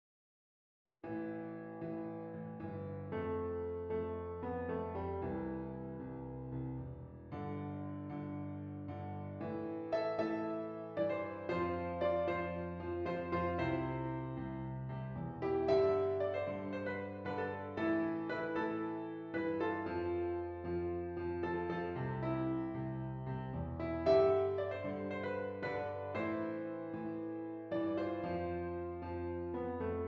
A Minor
Moderately